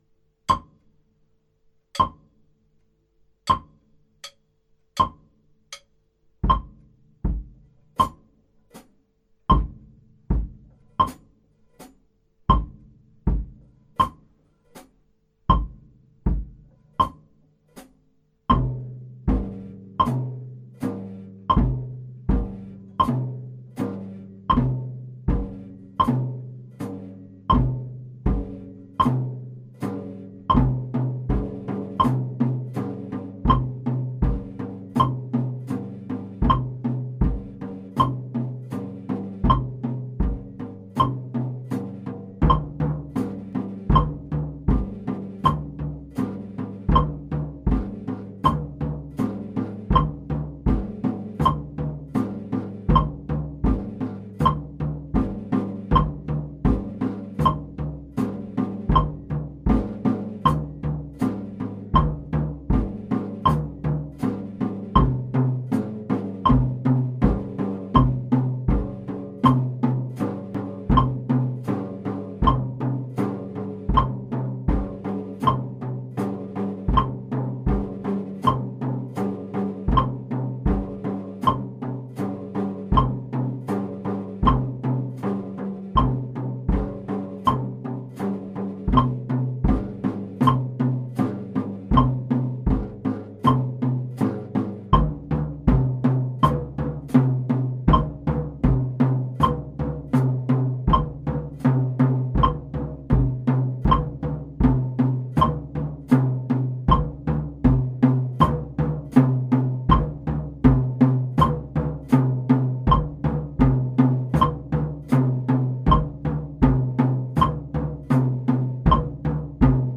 Schlagzeug
Die Füße doppeln von Anfang an, die Hände verdoppeln ihren Wechselschlag.
Und hier als 3 Minuten Spaziergang über das Drumset mit Metronom im Tempo 40.
doppelschlaege-drumset-40BPM.mp3